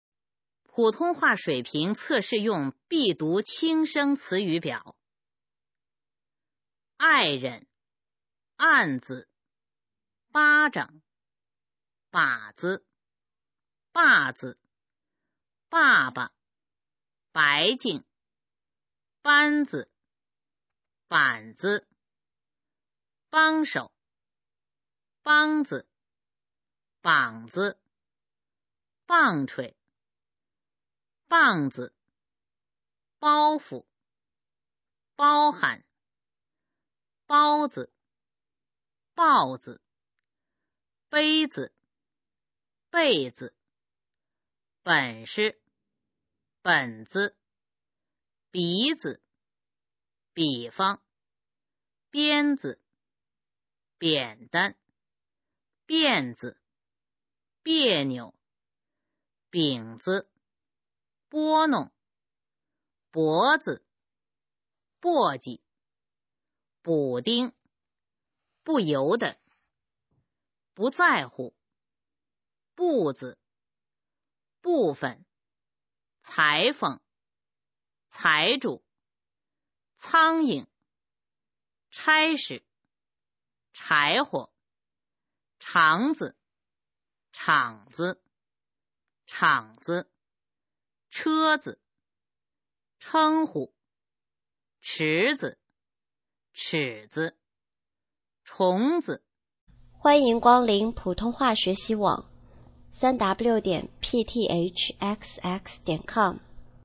普通话水平测试用必读轻声词语表示范读音第1-50条